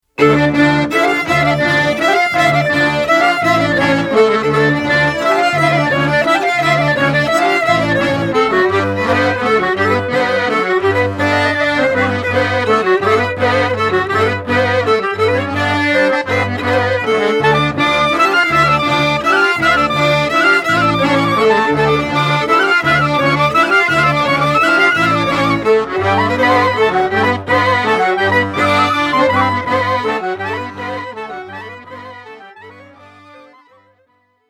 Violin
C Clarinet
Accordions, Tsimbl
Bass Cello
Genres: Klezmer, Polish Folk, Folk.